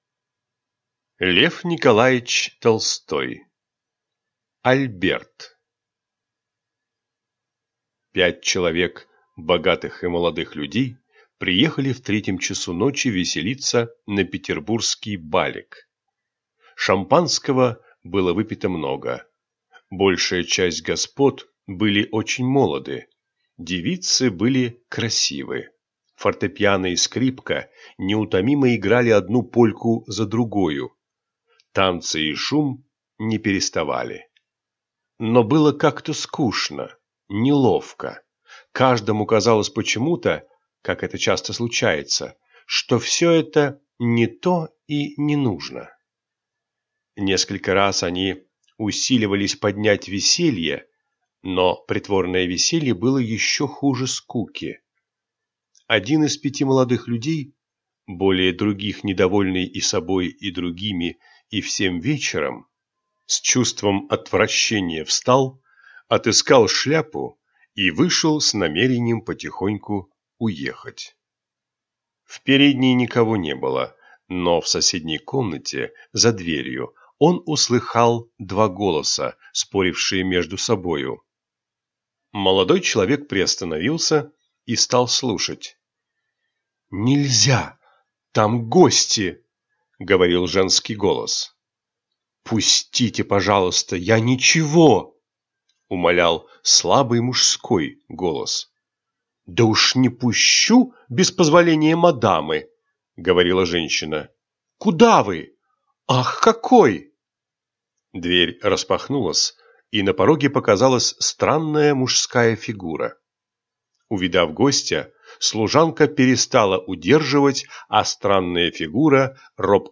Аудиокнига Альберт